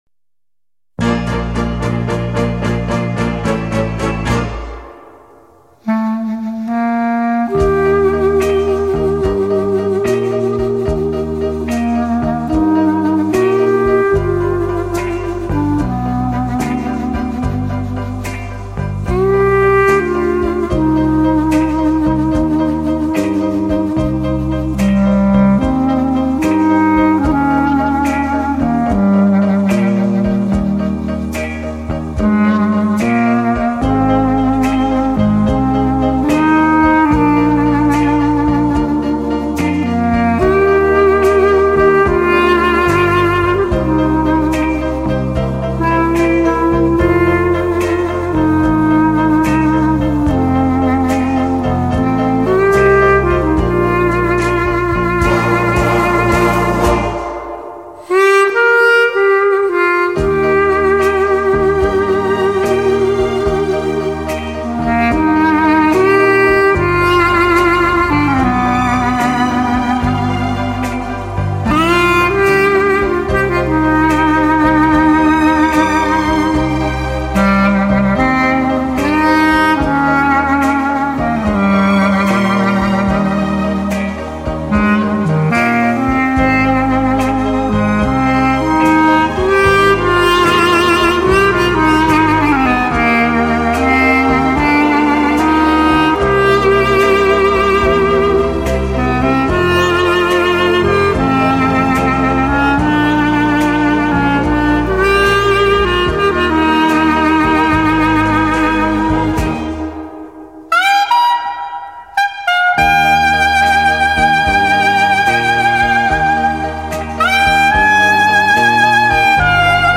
Clarinet)Year Of Release